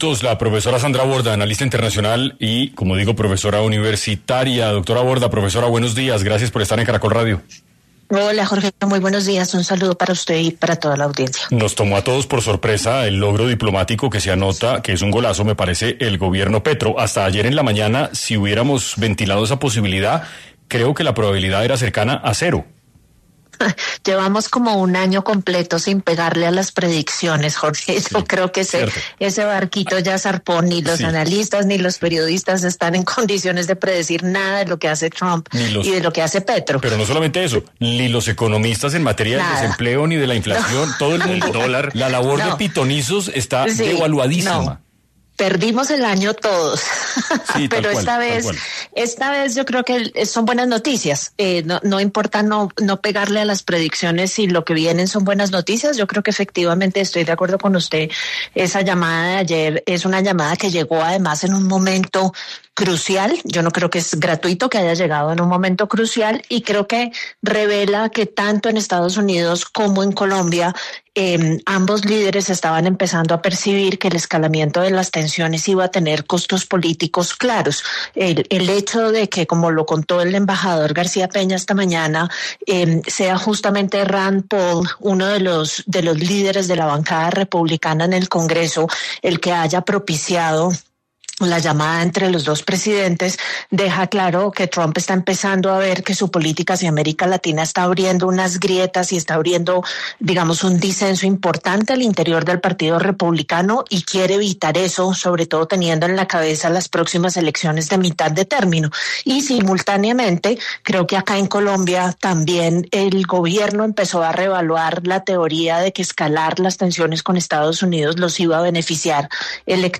En 6AM de Caracol Radio estuvo Sandra Borda, profesora y Alta Consejera para las Relaciones Internacionales, quien habló sobre lo que significa esta llamada entre los dos Estados